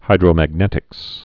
(hīdrō-măg-nĕtĭks)